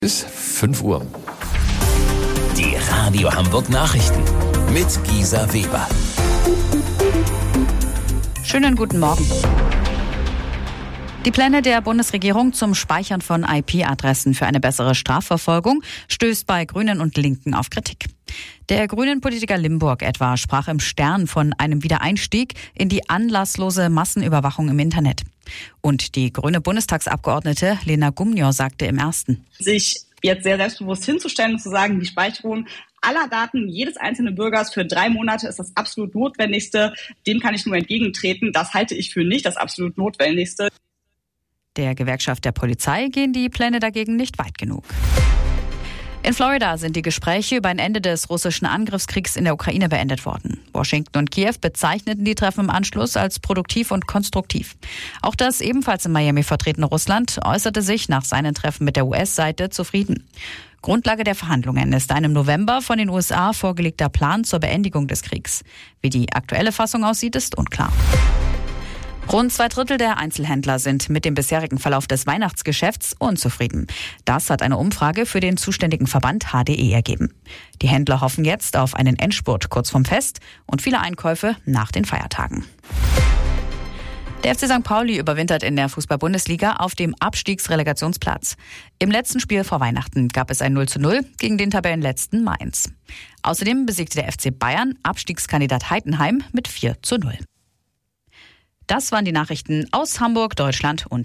Radio Hamburg Nachrichten vom 22.12.2025 um 05 Uhr